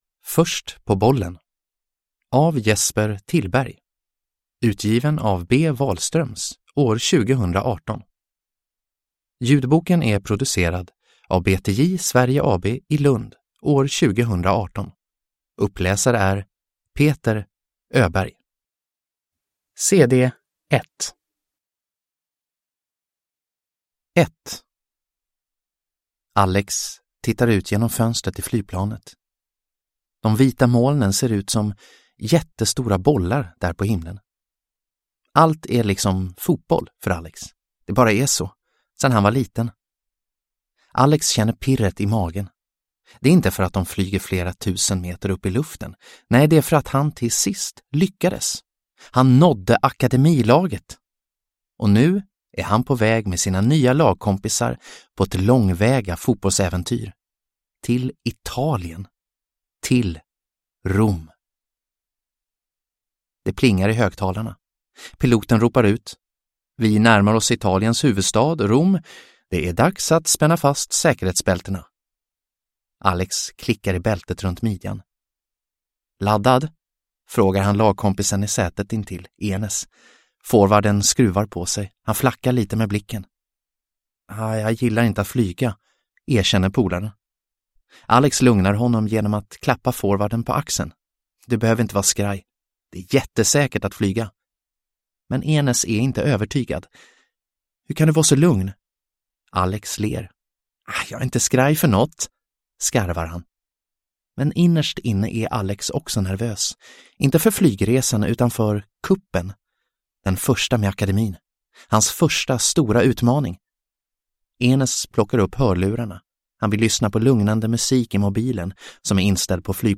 Först på bollen – Ljudbok – Laddas ner